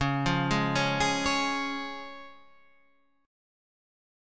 C# Chord
Listen to C# strummed